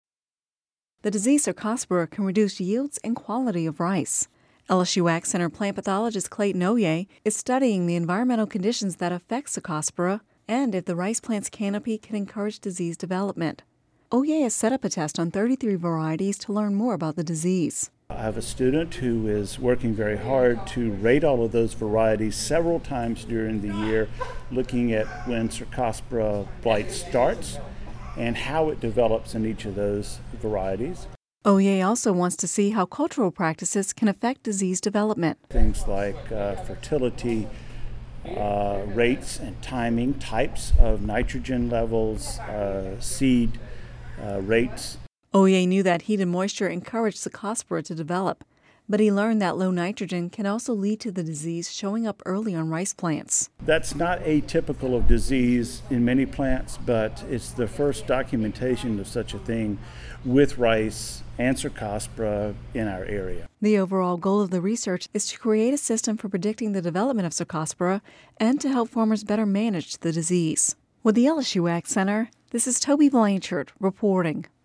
(Radio News 07/14/11) The disease Cercospora can reduce yields and quality of rice.